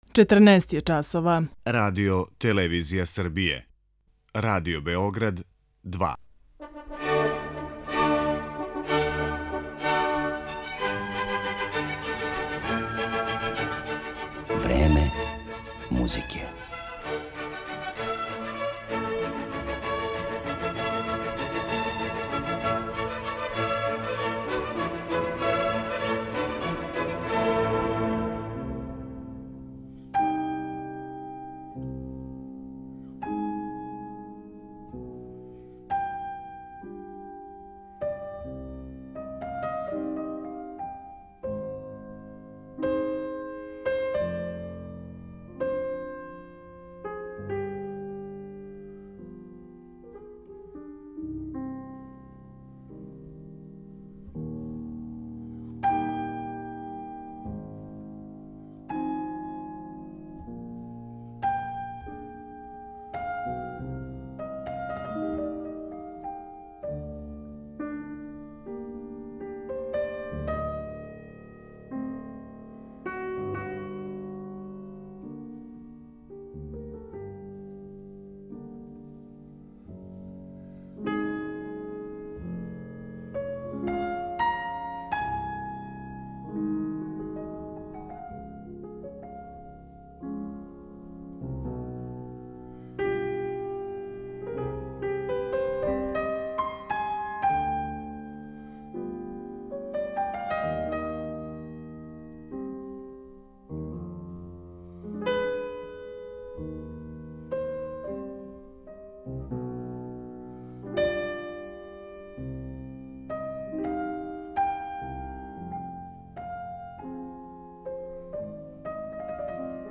Његов јединствени извођачки приступ биће скициран интерпретацијама дела Шопена, Мусоргског, Рахмањинова, Скарлатија, Равела и Чајковског, уз кратак интервју са пијанистом.